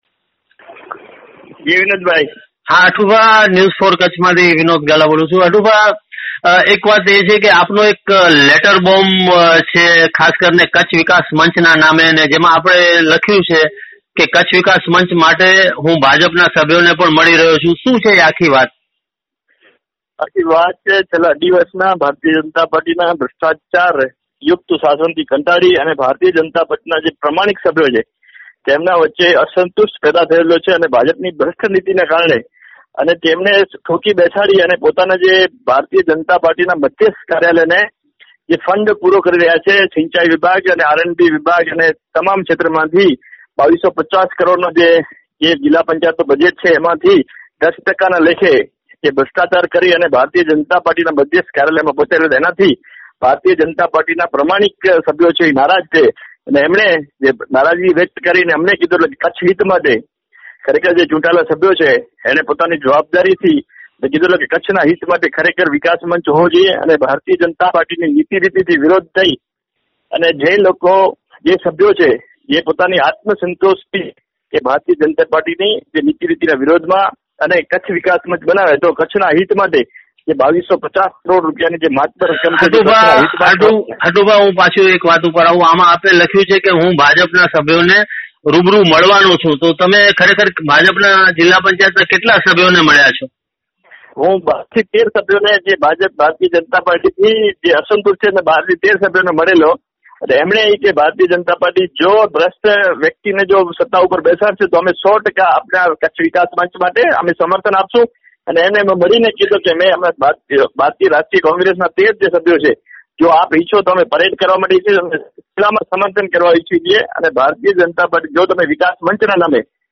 હવે જ્યારે પ્રમુખ અને ઉપપ્રમુખનું કાઉન્ટડાઉન ચાલુ છે ત્યારે મૂળ ભાજપી ગોત્ર ધરાવતા અને કચ્છ જિલ્લા પંચાયતની સામાન્ય સભાને ગજાવતા કોંગ્રેસના સભ્ય હઠુભા સોઢાએ શું કહ્યુ તે માટે સાંભળો ન્યૂઝ4કચ્છની આ ઓડિયો લીંક અને જાણો જિલ્લા પંચાયતનો નવો રાજકીય સળવળાટ.